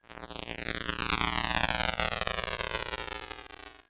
Machine08.wav